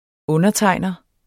Udtale [ -ˌtɑjˀnʌ ]